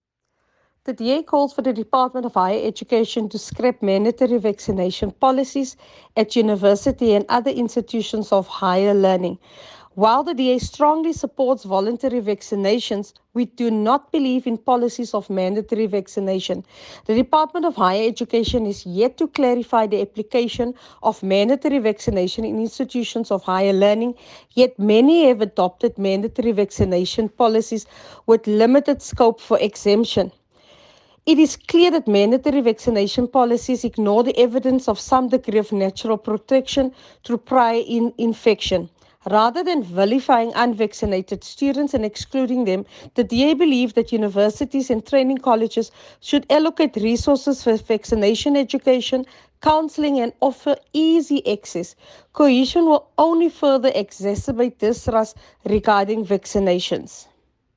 soundbite by Chantel King MP.